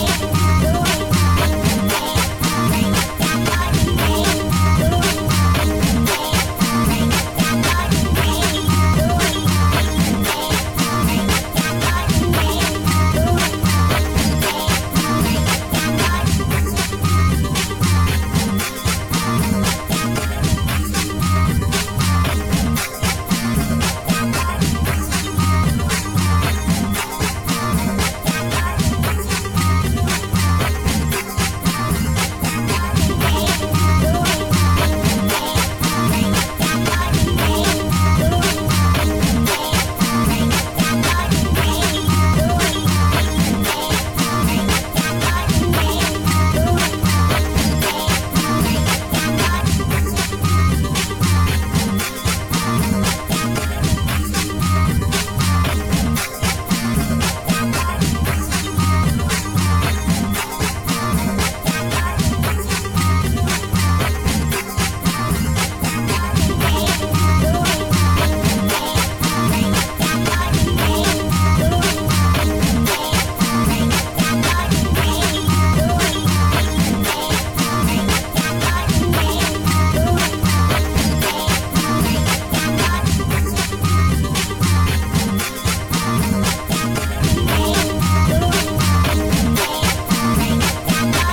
Another old loop music I tested with Virtual DJ 2023, I am currently testing out more features.